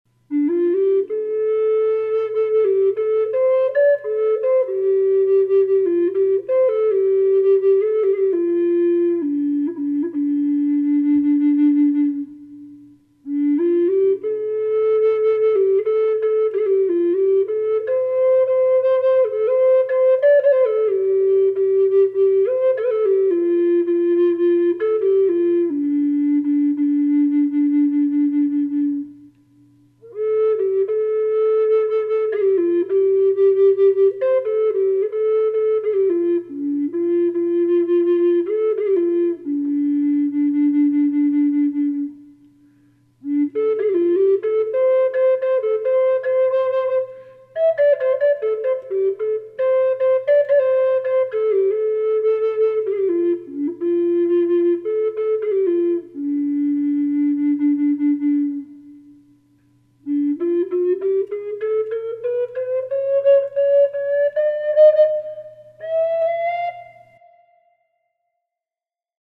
Colorado Quacking Aspen Bottle nose Dolphin inlay Flute in the key of low Dm.
Gorgeous voice & tone
Sound sample with light Reverb
dolphin-aspen-d-minor-reverb-1.mp3